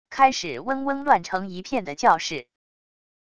开始嗡嗡乱成一片的教室wav音频